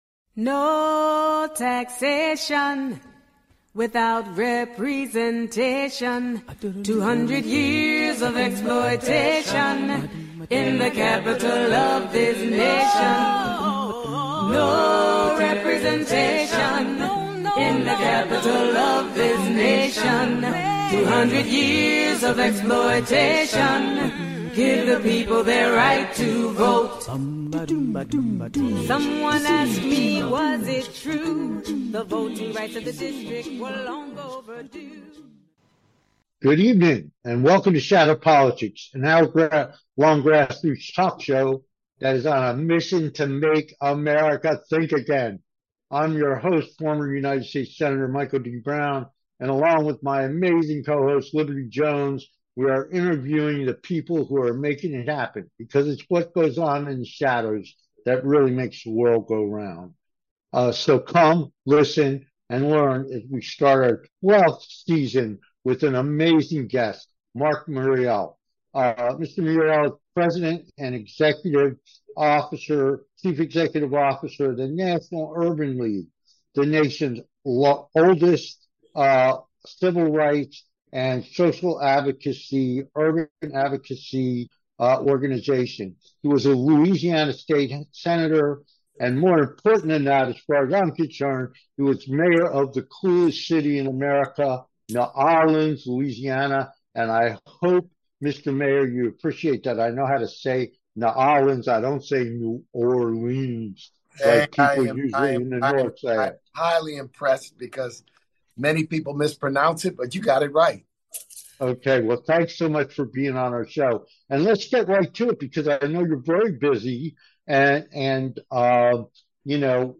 Guest Marc H. Morial - President and Chief Executive Officer of the National Urban League